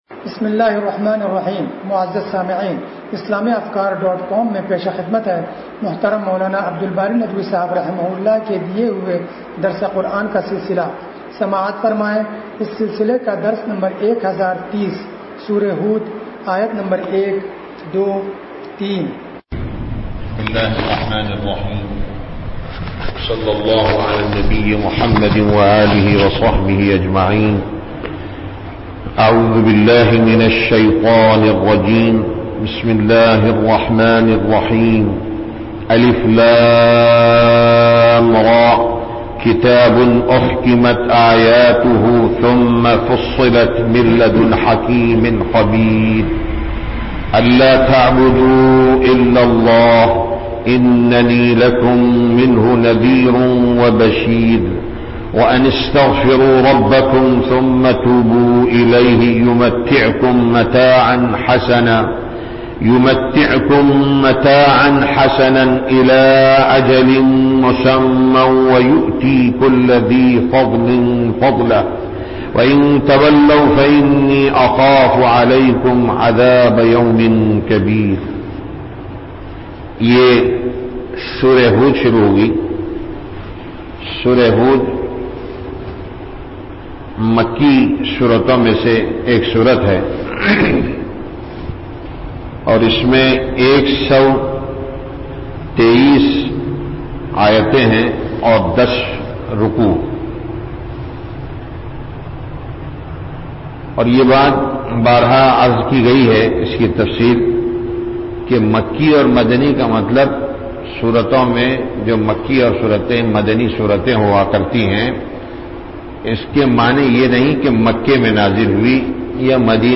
درس قرآن نمبر 1030